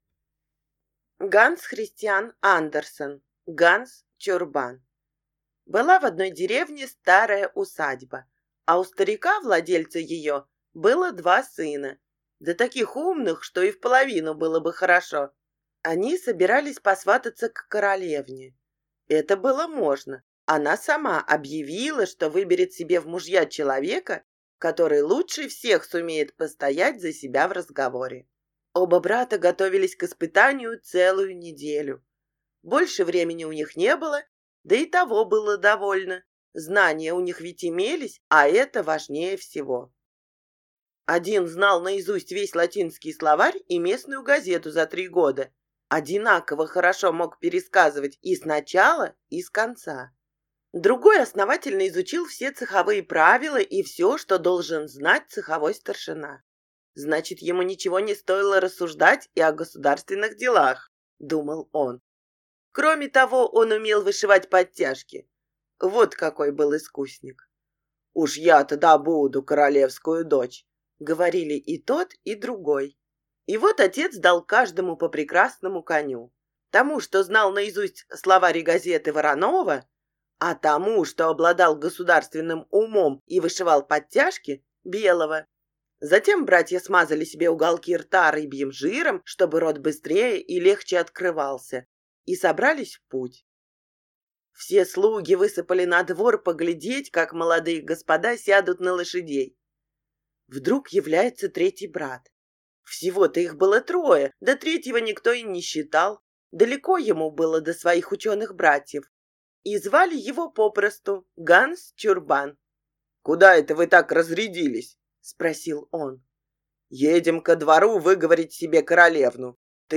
Аудиокнига Дурень Ганс | Библиотека аудиокниг
Прослушать и бесплатно скачать фрагмент аудиокниги